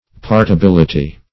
Search Result for " partibility" : The Collaborative International Dictionary of English v.0.48: Partibility \Part`i*bil"i*ty\, n. [From Partible .]